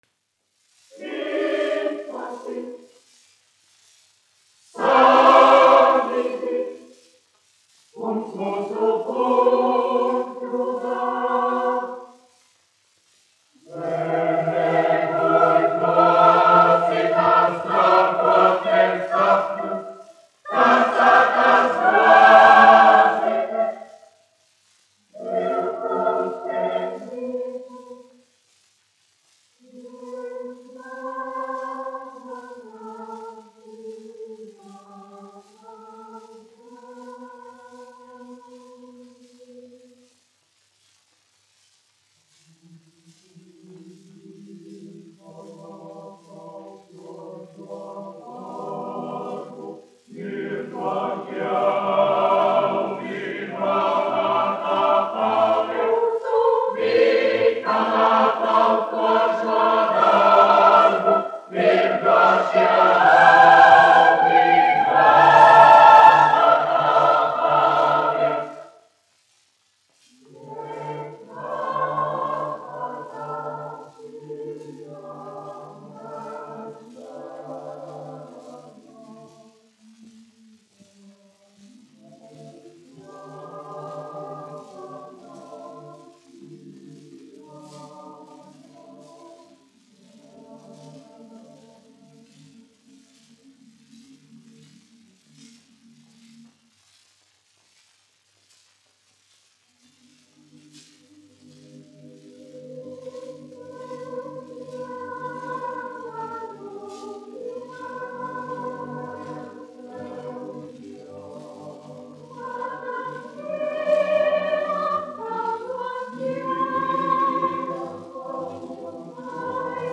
Reitera koris, izpildītājs
1 skpl. : analogs, 78 apgr/min, mono ; 25 cm
Kori (jauktie)
Latvijas vēsturiskie šellaka skaņuplašu ieraksti (Kolekcija)